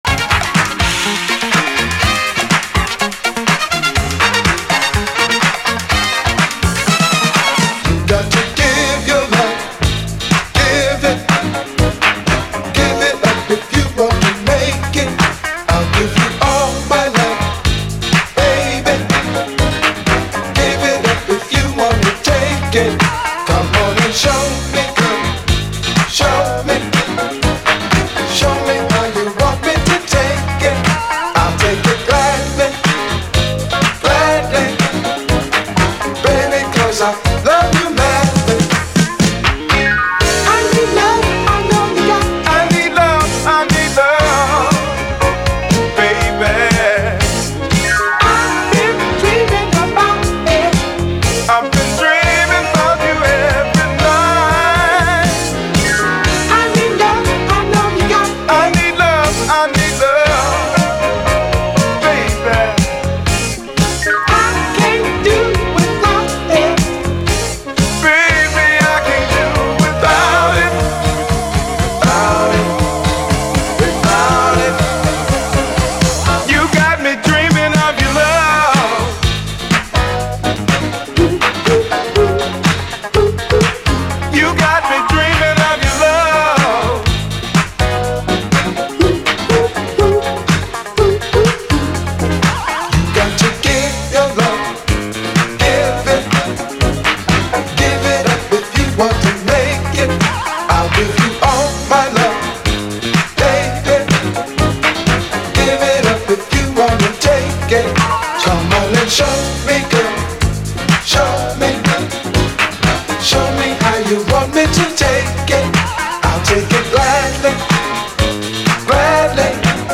SOUL, 70's～ SOUL
ブギーな躍動感の79年モダン・ソウル・ダンサー！